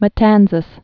(mə-tănzəs, mä-tänsäs)